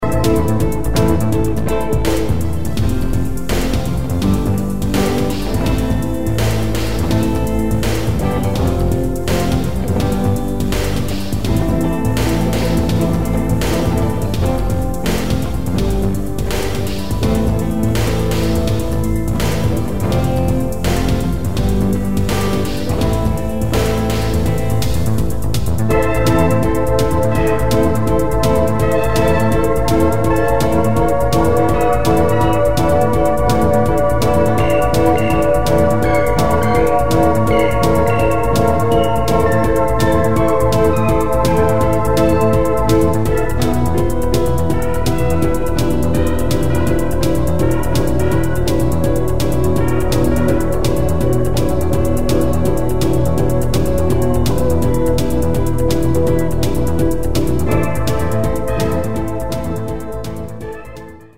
Ein instrumentaler Keyboardsong